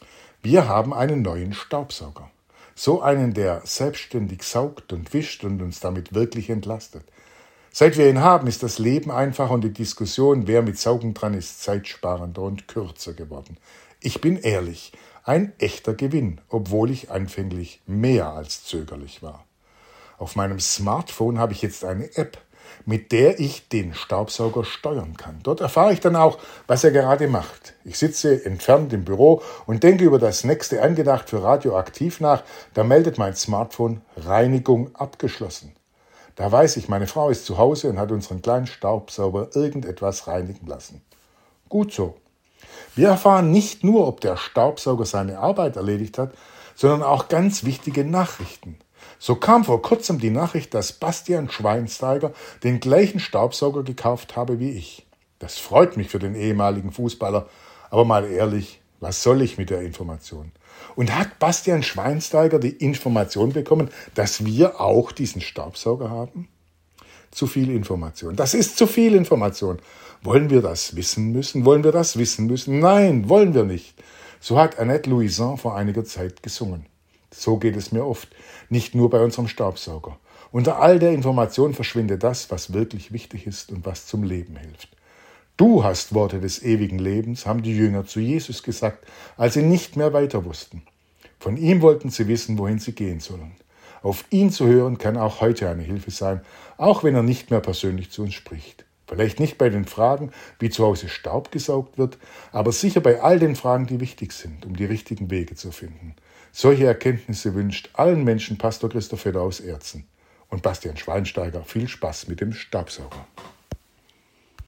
Radioandacht vom 17. September